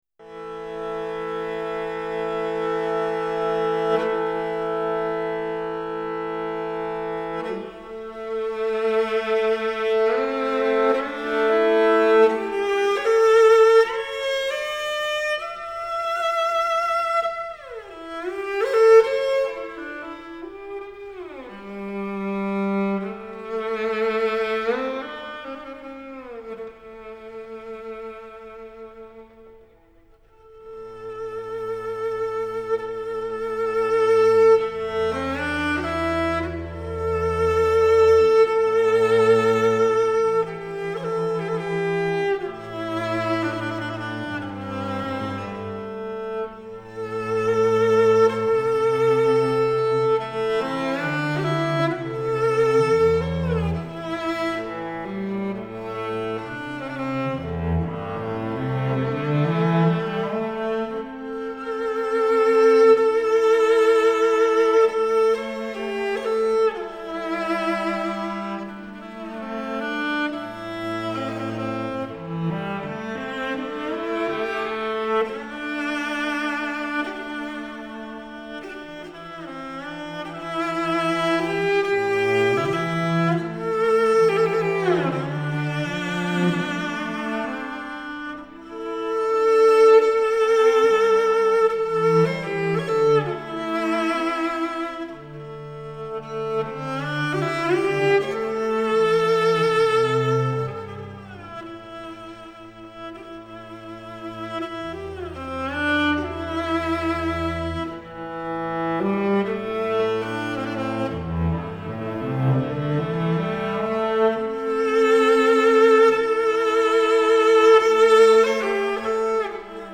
马头琴，因声音里带着草原的美感而充满神秘和想象
这是草原的音乐盛宴、也是草原音乐之旅